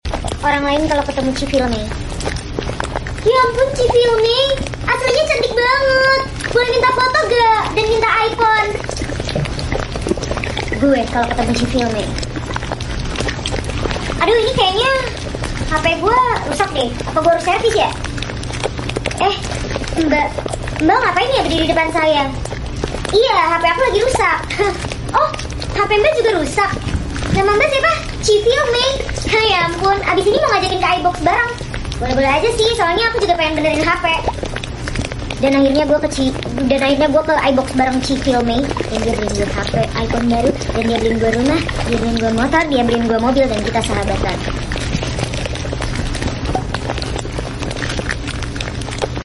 cafe sound sound effects free download